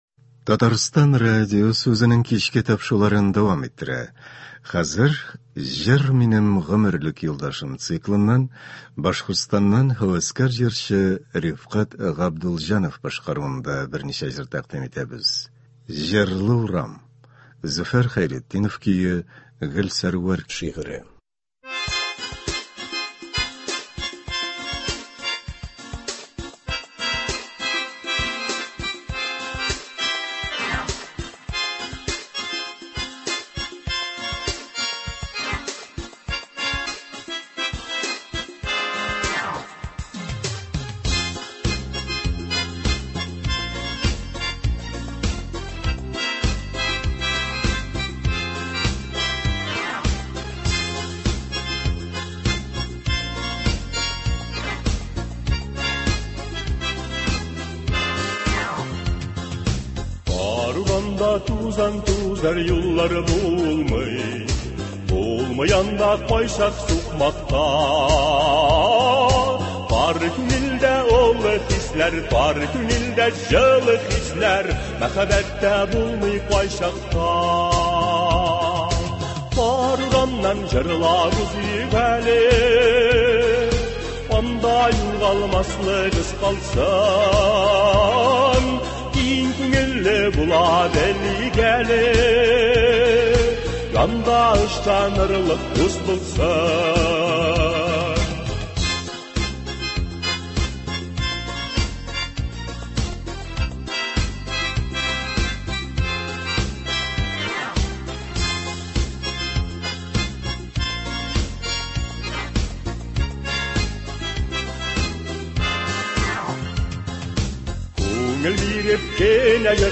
Үзешчән башкаручылар чыгышы.
Концерт (15.04.24)